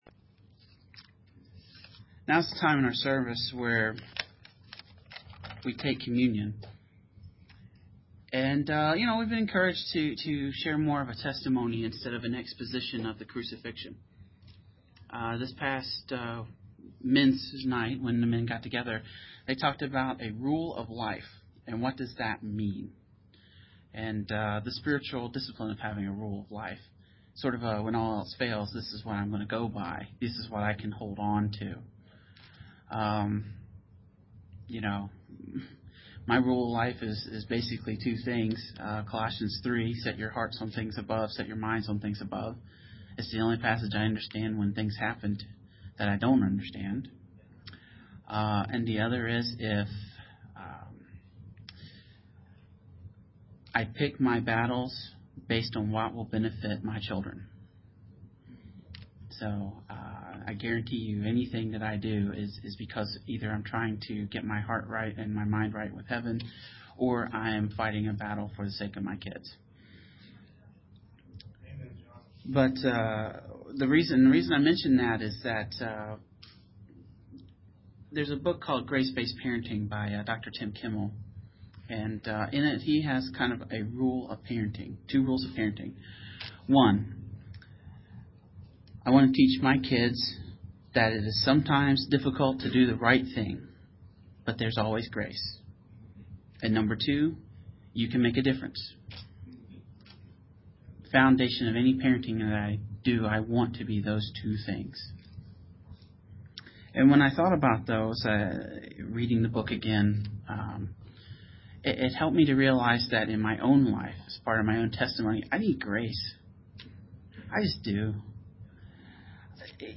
I did communion this past Sunday. Thanks to my friend on the sound board, it was recorded.